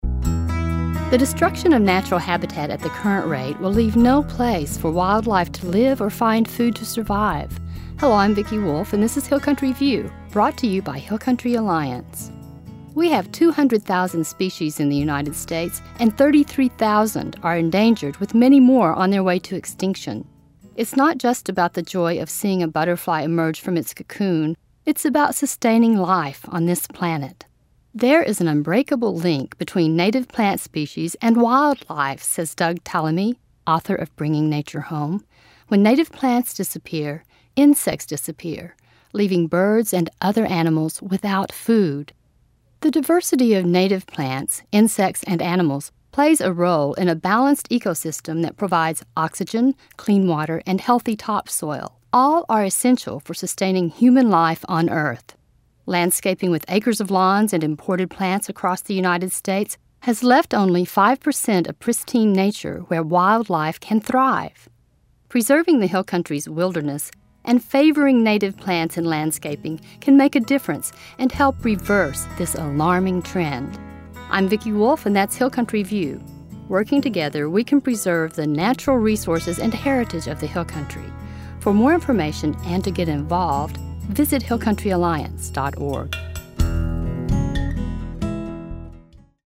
90 Second Radio Spots